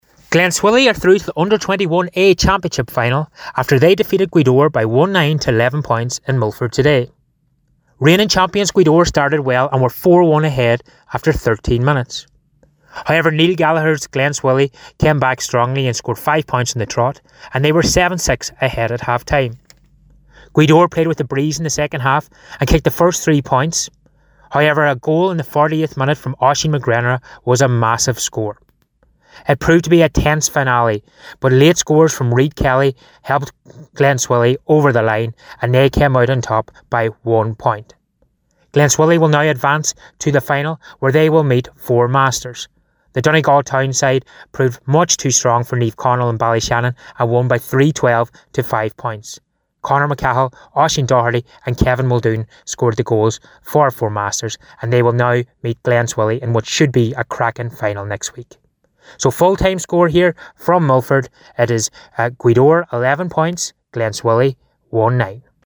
was in Milford for Highland Radio Sunday Sport…